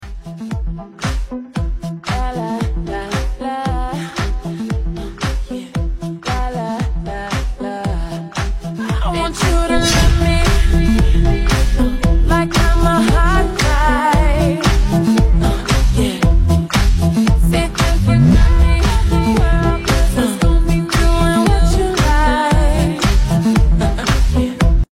A Gang Of Ford Mustangs Sound Effects Free Download